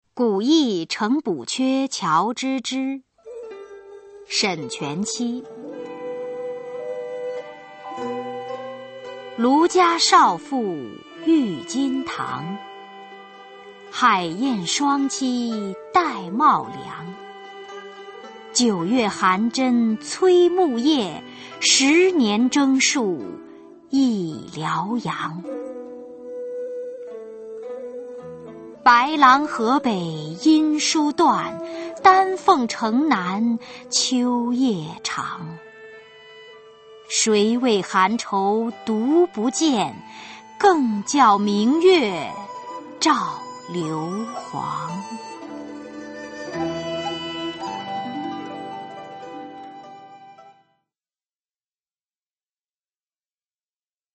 [隋唐诗词诵读]沈全期-古意呈补阙乔知之 配乐诗朗诵